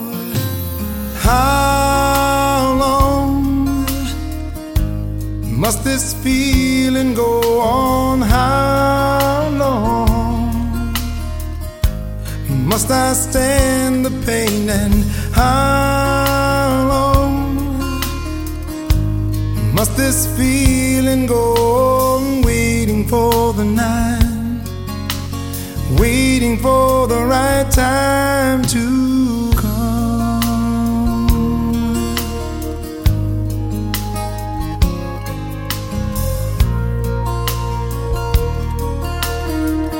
поп
романтические , баллады